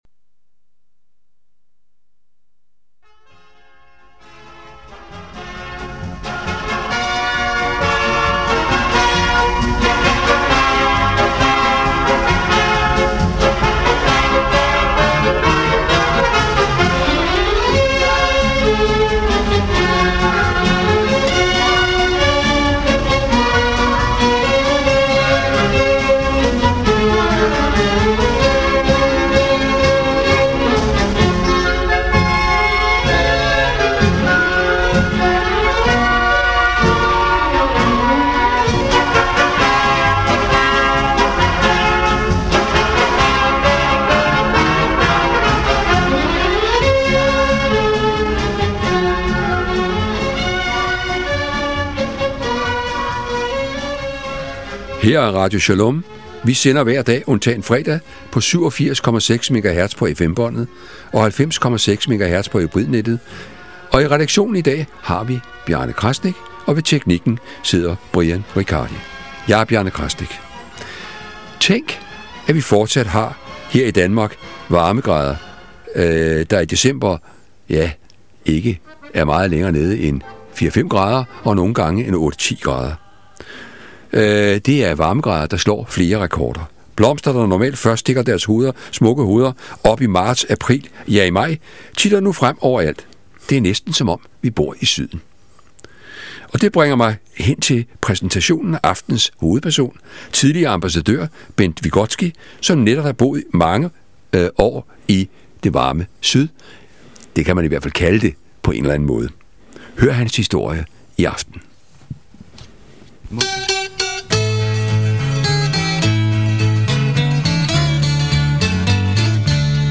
Beskrivelse: Interview